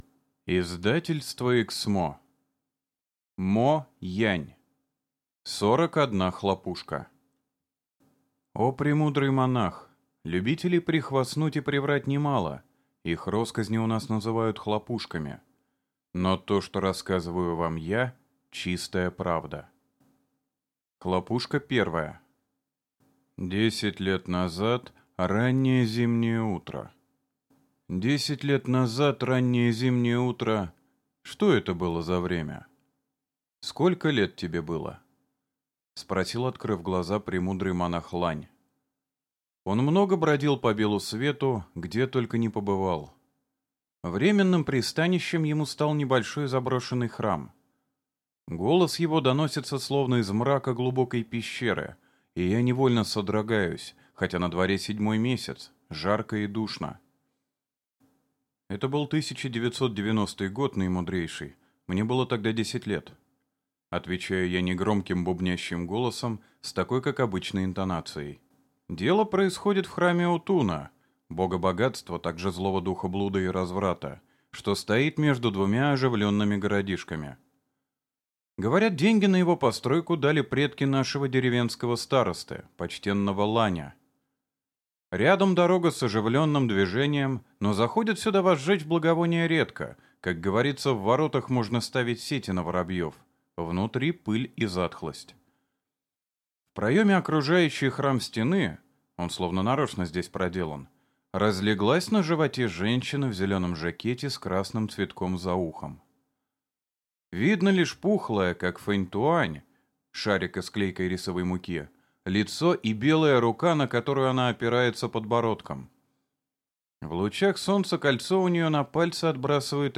Аудиокнига Сорок одна хлопушка | Библиотека аудиокниг